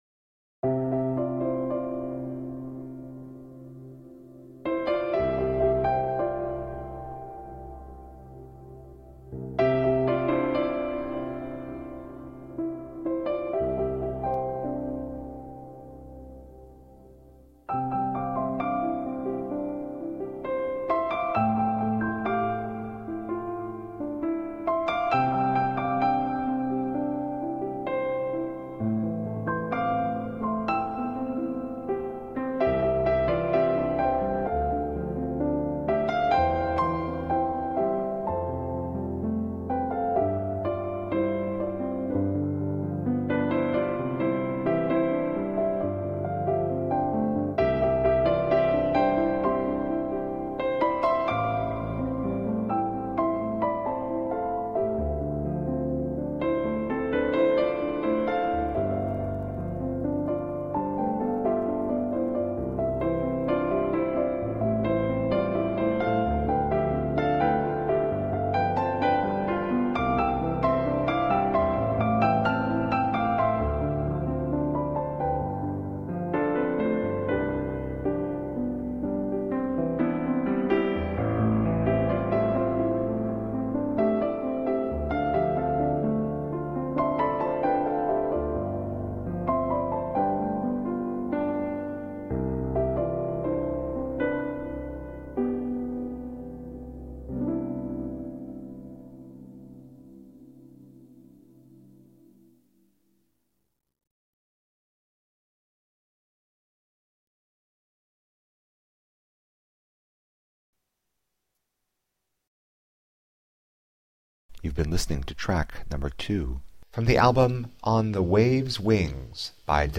New age romantic piano music.